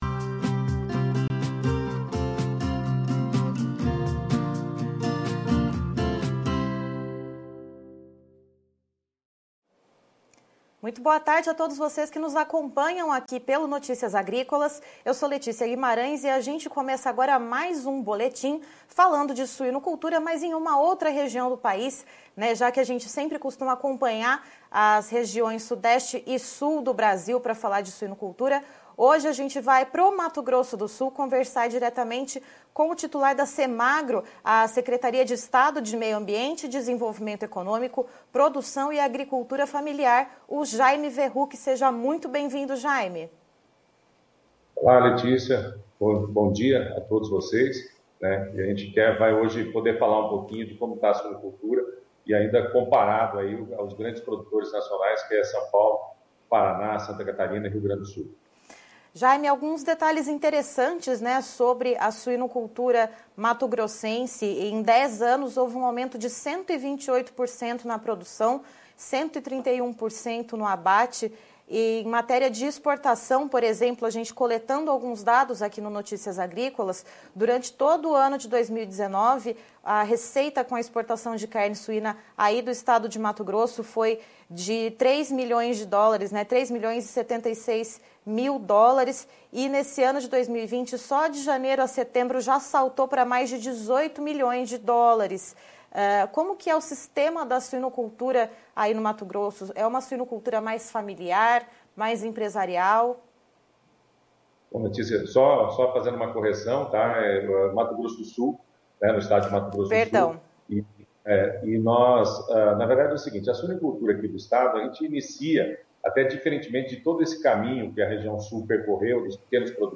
Entrevista com Jaime Verruck - Titular da Semagro sobre a Suinocultura no MS